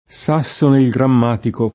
S#SSone il gramm#tiko] (o in forma lat. Saxo Grammaticus [S#kSo gramm#tikuS]), storico danese (m. 1210) — cfr. frisone; lappone; Svizzera